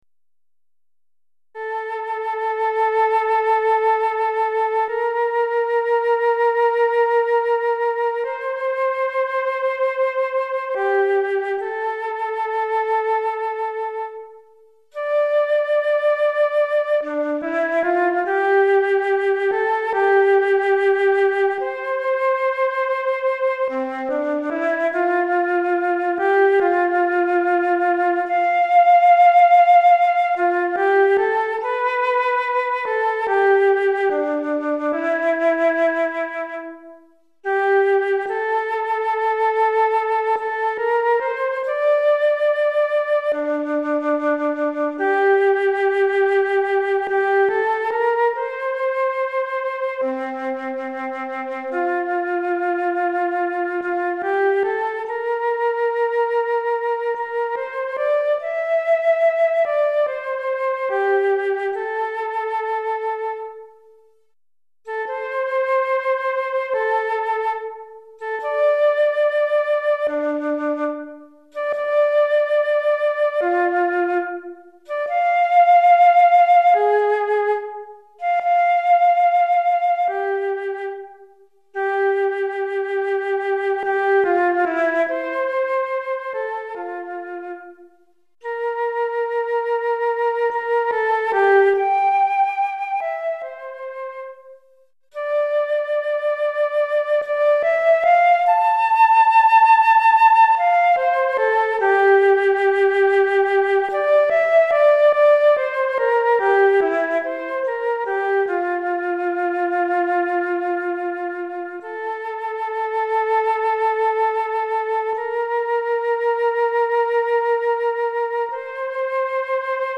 Flûte Traversière Solo